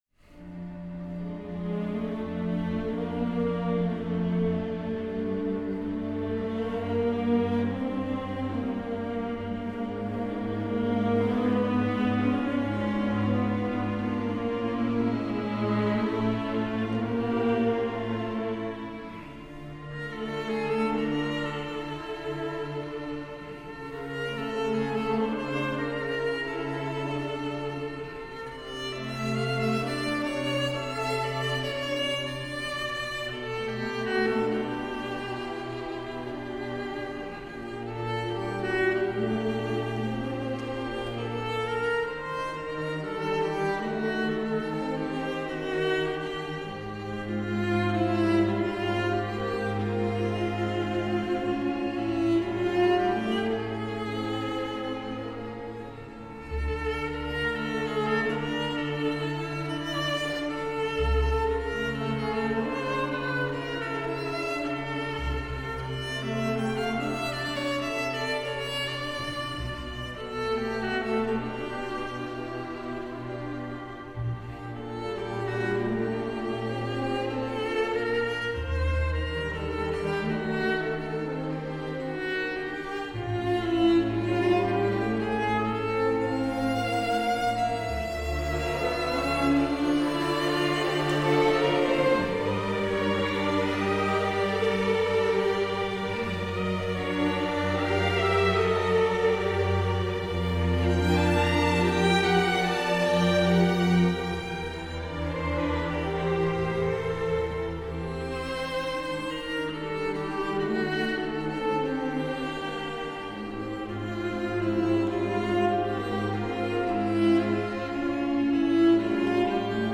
for viola solo and string orchestra